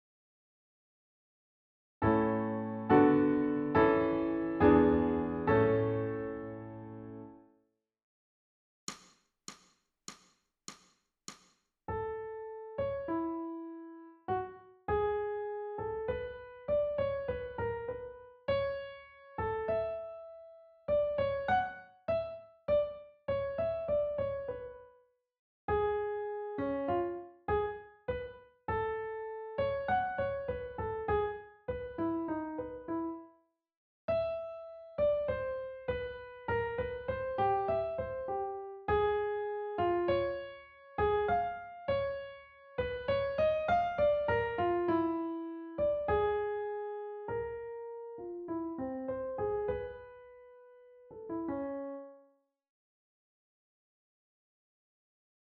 ソルフェージュ 聴音: 2-1-47